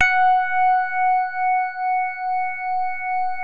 JAZZ SOFT#F4.wav